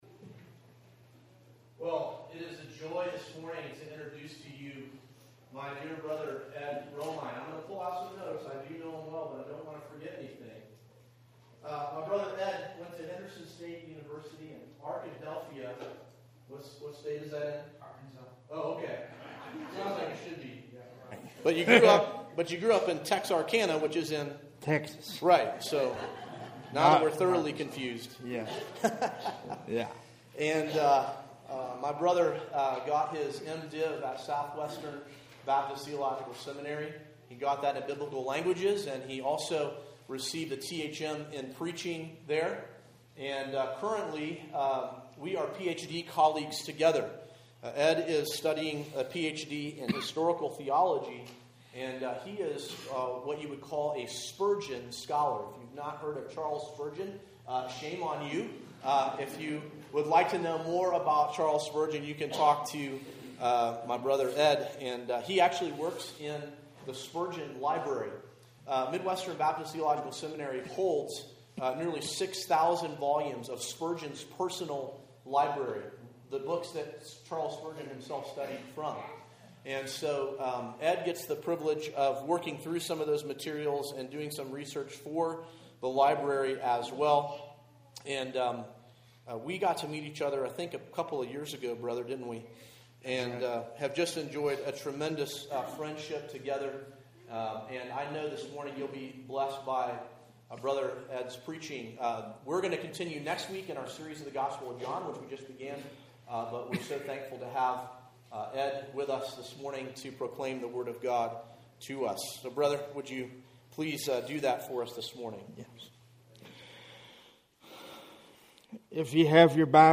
An exposition